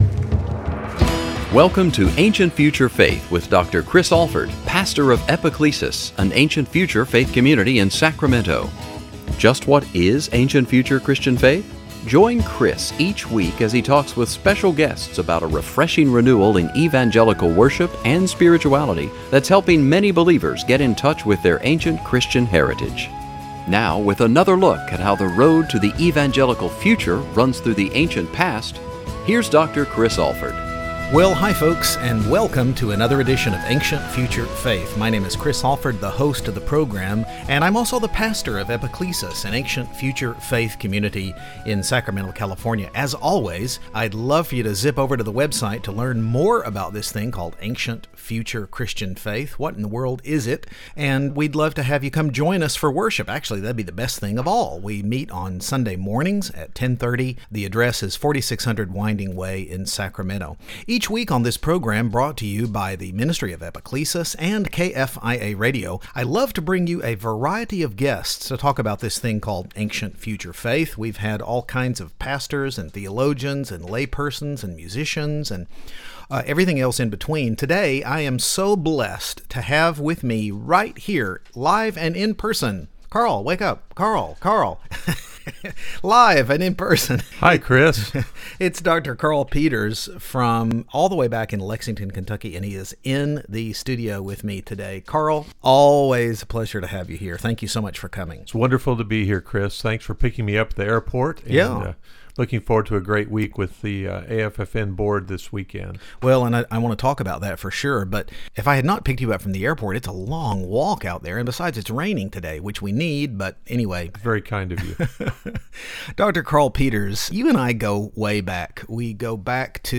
radio studio